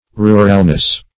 Ruralness \Ru"ral*ness\, n. The quality or state of being rural.